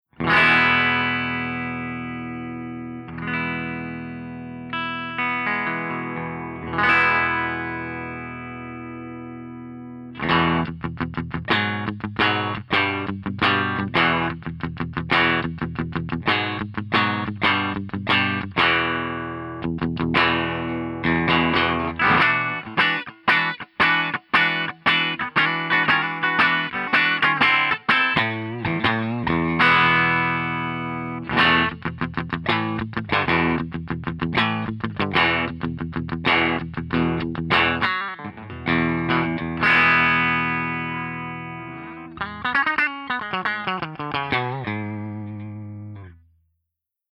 047_FENDER75_PUNCHYEQ_P90